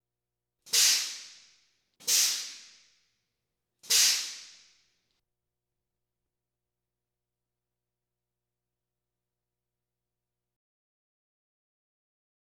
Truck Air Brakes Interior 1